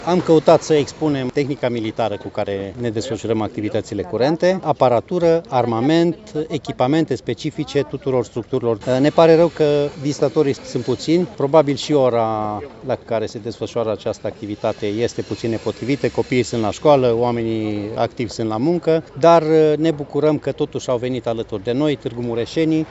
Expoziția de tehnică militară a deschis Ziua Armatei Române, care a adus câteva zeci de militari în paradă.